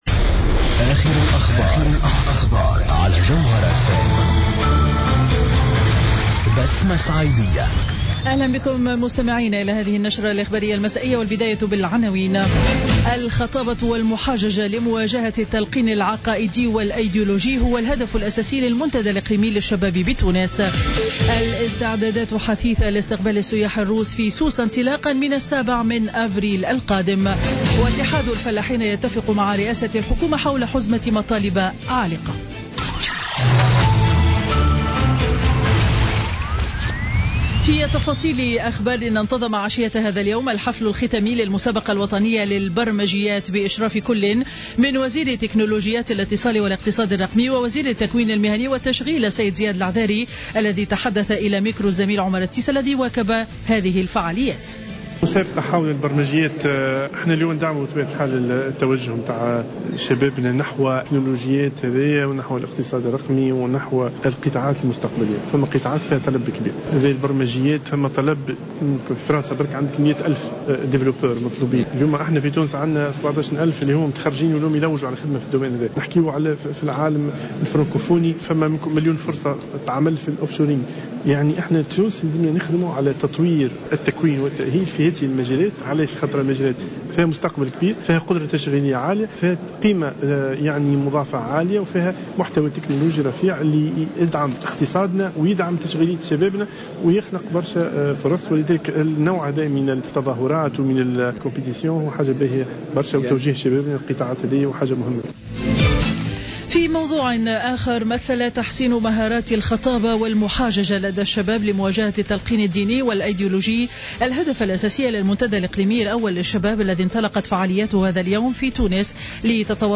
نشرة أخبار السابعة مساء ليوم الخميس 24 مارس 2016